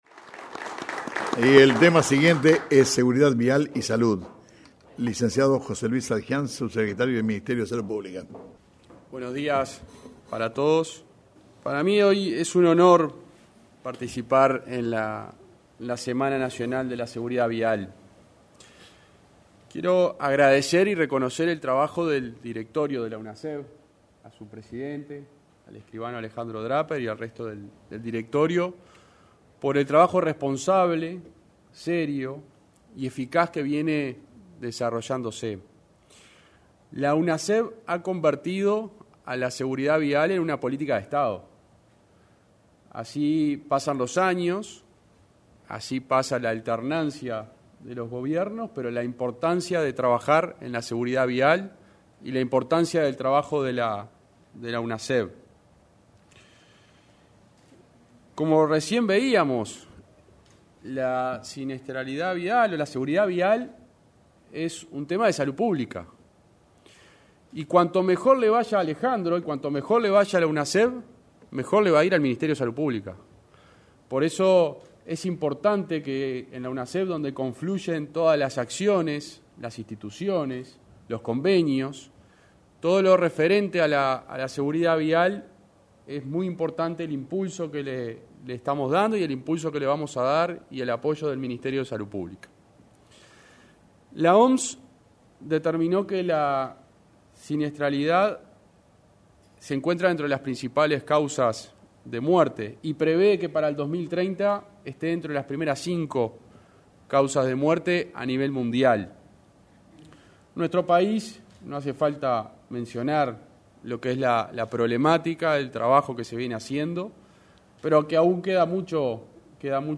Exposición del subsecretario de Salud Pública.
Exposición del subsecretario de Salud Pública. 29/10/2021 Compartir Facebook X Copiar enlace WhatsApp LinkedIn En el marco de la XIV Semana Nacional de la Seguridad Vial, el subsecretario de Salud Pública, José Luis Satdjian, realizó una presentación sobre seguridad vial y salud.